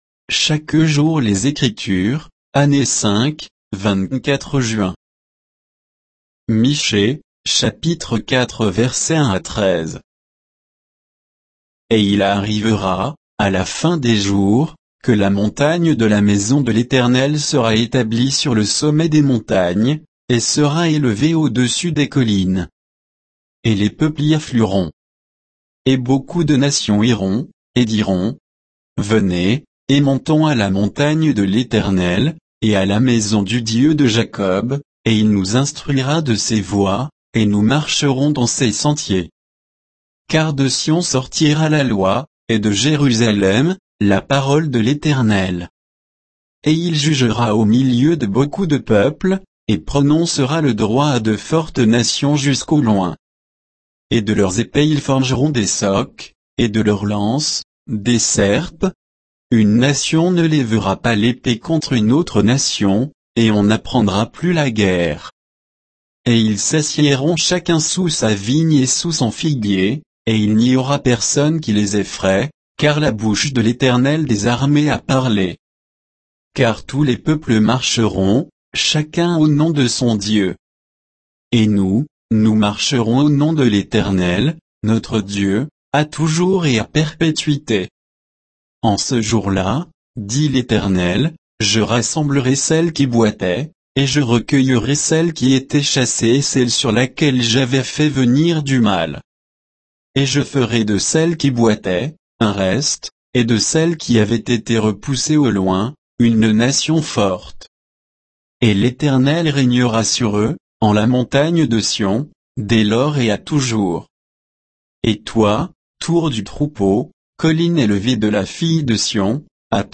Méditation quoditienne de Chaque jour les Écritures sur Michée 4